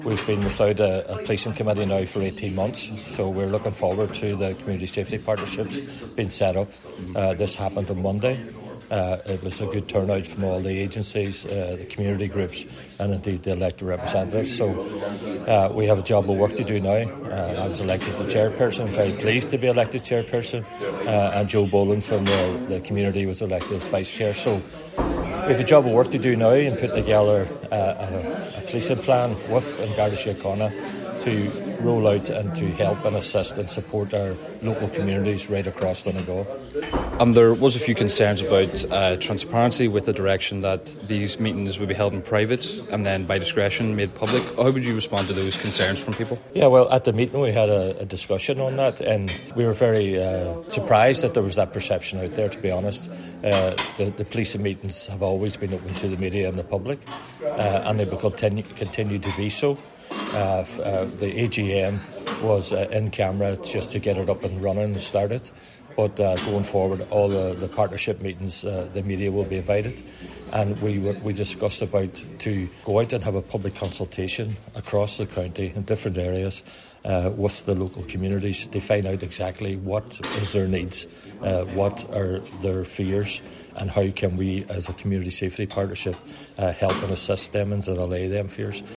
Cllr Gerry McMonagle has been elected as Chairperson.
He says the partnership will help support local communities in allaying any fears they may have:
gerry-mcmonagle-lsp-1pm.mp3